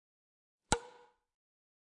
在大学院橄榄球比赛录音
描述：在大学院橄榄球比赛录音，地面声音，啦啦队员，口哨和体育场群众声音
Tag: 啦啦队 欢呼 人群 橄榄球